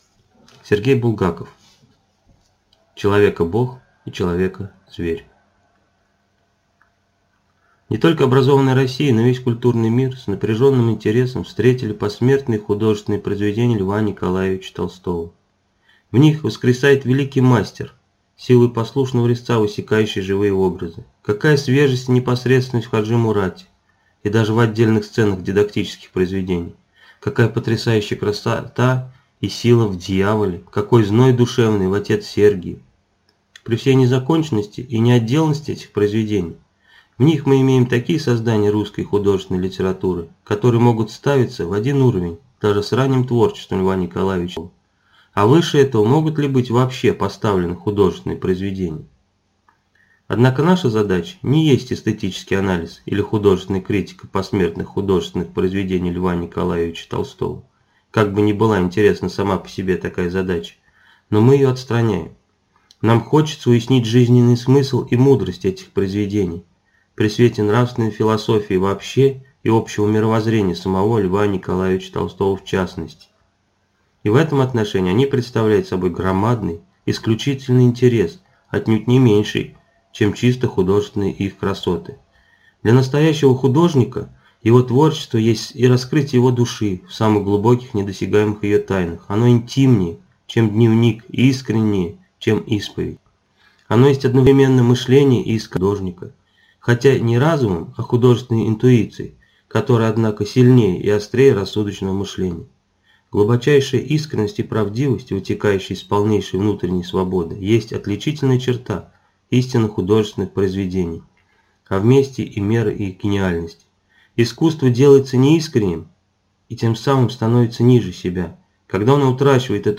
Аудиокнига Человекобог и человекозверь | Библиотека аудиокниг